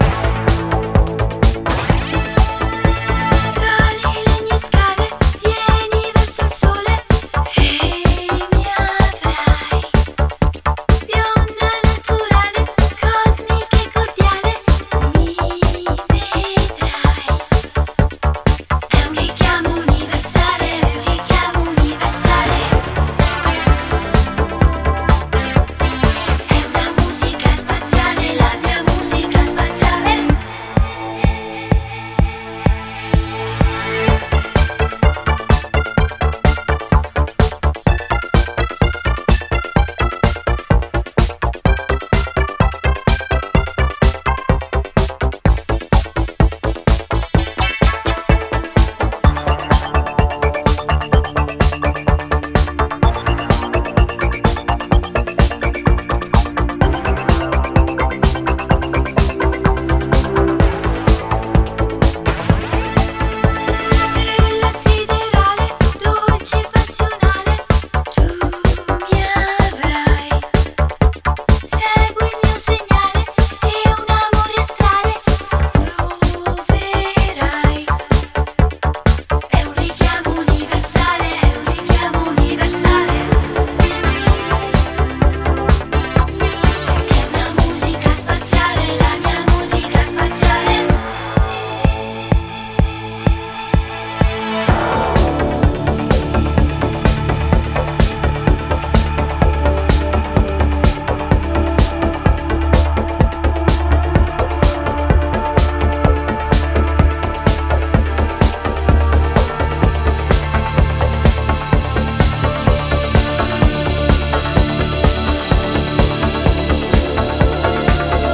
An Italian disco classic
is a fast paced synthy disco number